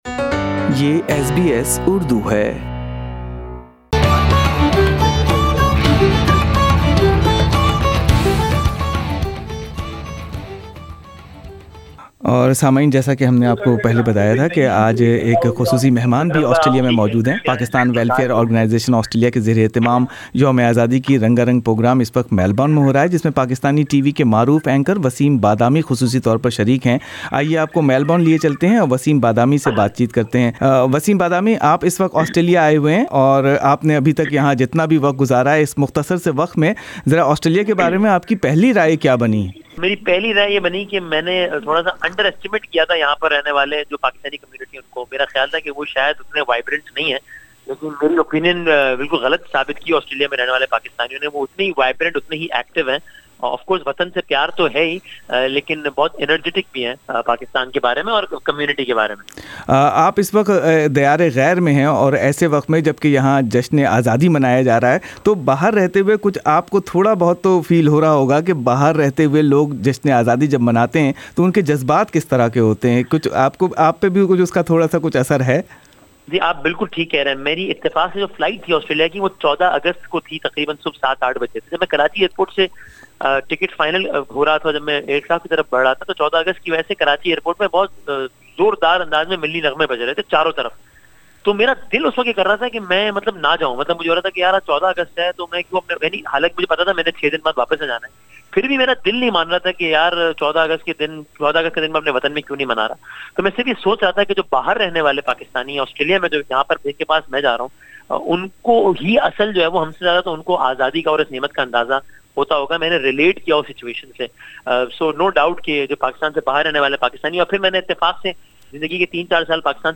...Listen interesting and innocent Q/A with visiting guest anchor.